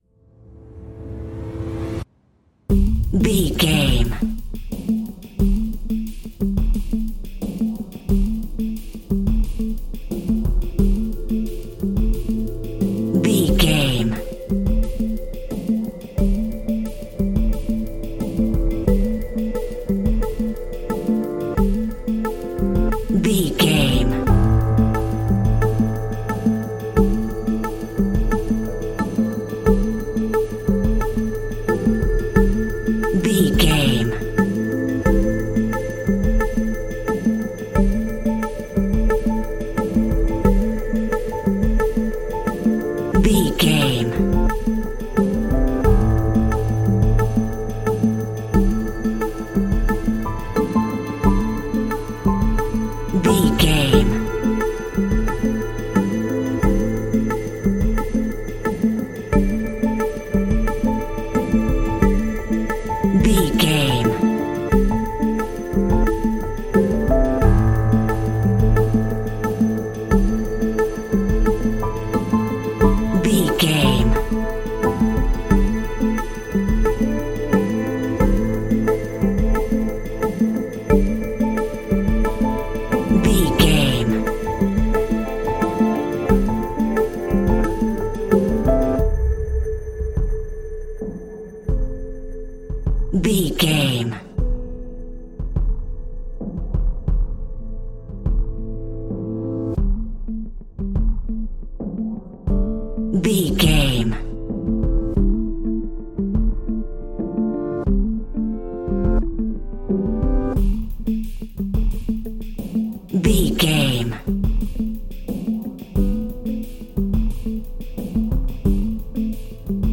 Ionian/Major
electronic
techno
trance
synthesizer
synthwave
glitch